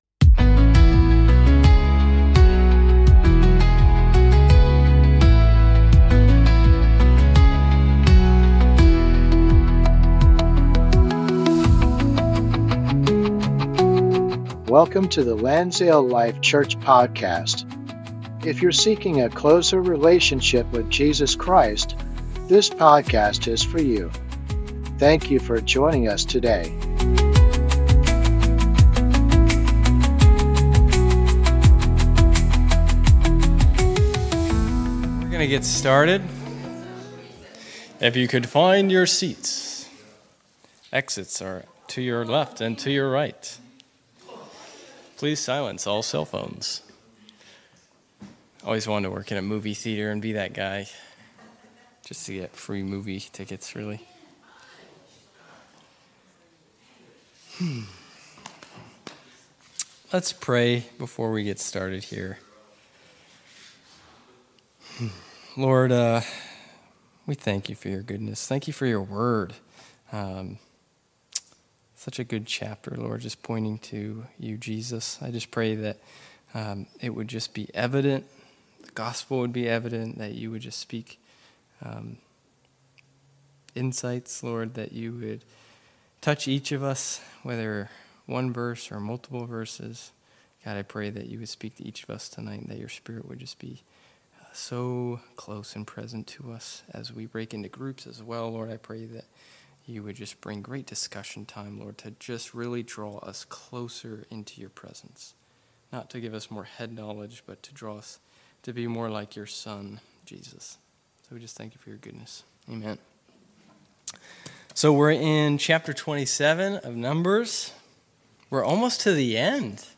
An ongoing study of the book of Numbers. This week’s lesson